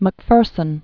(mək-fûrsən), James 1736-1796.